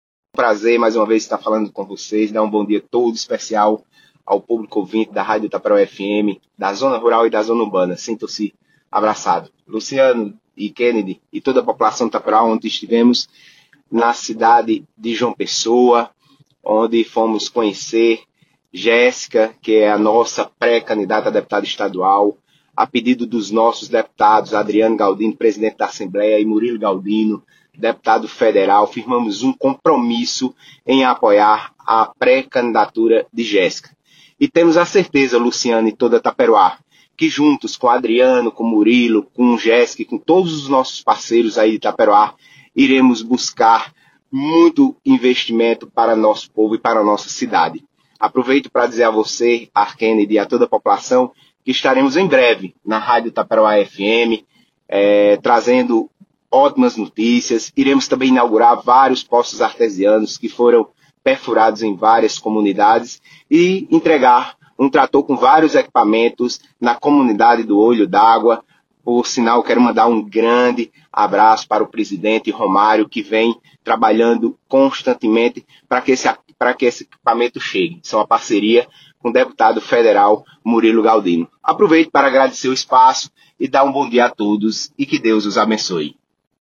participou nesta semana do programa jornalístico da Rádio Taperoá FM
cumprimentou os apresentadores